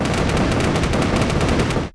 sfx_skill 11_3.wav